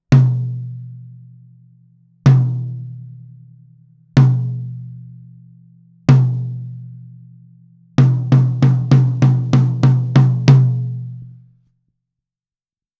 実際の録り音
タム
57ドラムタム.wav